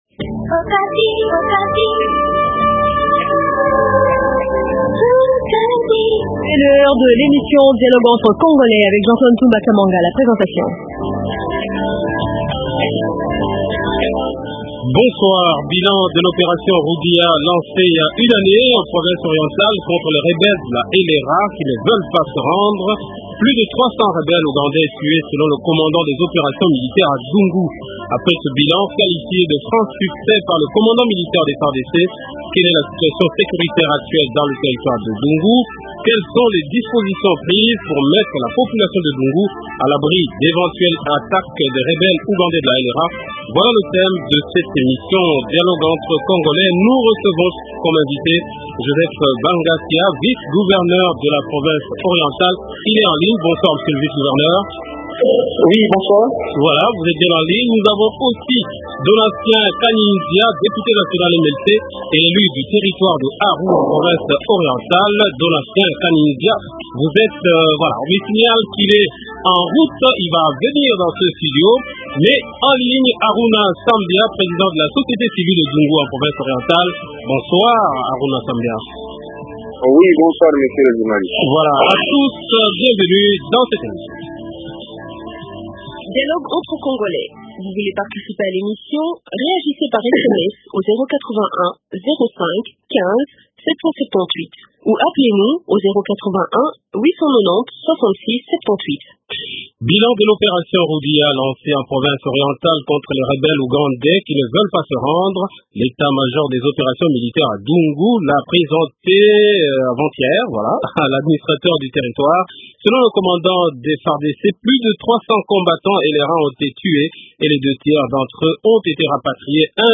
Invité -Joseph Bangakya, Vice gouverneur de la Province Orientale. -Donatien Kanyi Nzia, Député national Mlc et élu du territoire de Aru en Province Orientale.